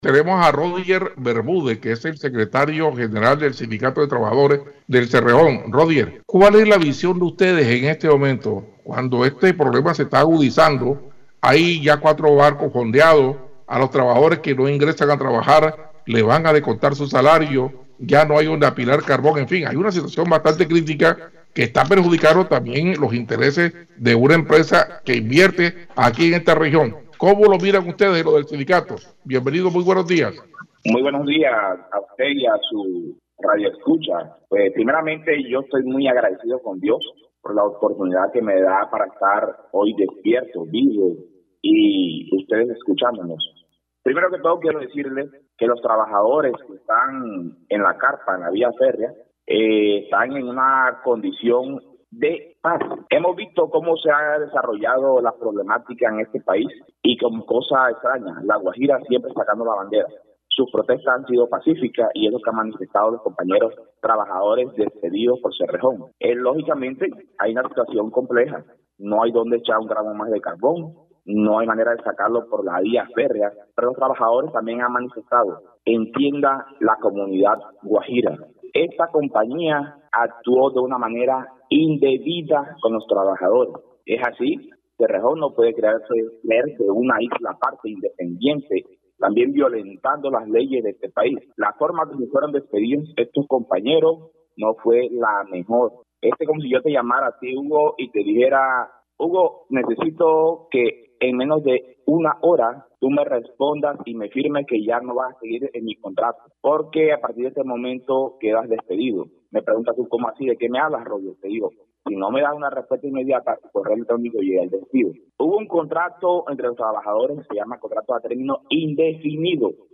En conversación con Diario del Norte, señaló que los trabajadores en la carpa están en una condición de paz.